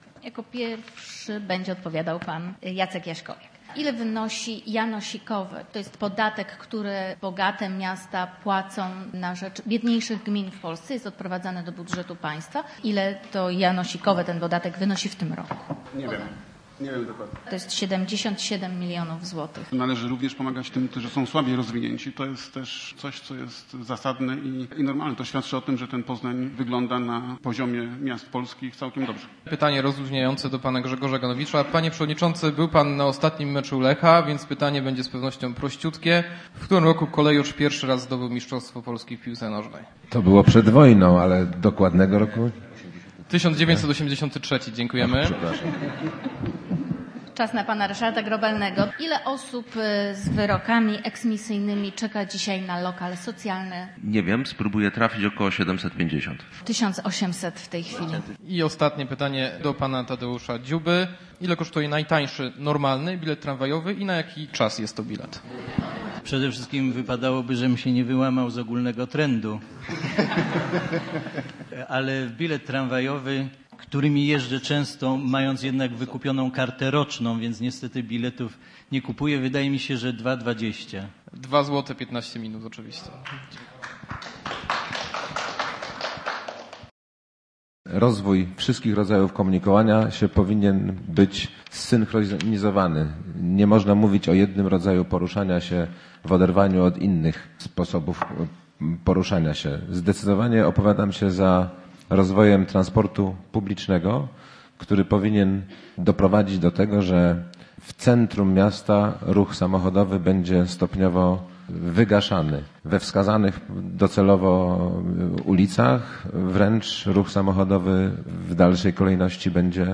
Debata prezydencka - o Poznaniu
Kandydaci na prezydenta Poznania spotkali się we wtorek na pierwszej w tej kampanii, wspólnej debacie o przyszłości miasta. Tadeusz Dziuba, Grzegorz Ganowicz, Ryszard Grobelny i Jacek Jaśkowiak odpowiadali na pytania przygotowane wspólnie przez dziennikarzy Radia Merkury i Głosu Wielkopolskiego.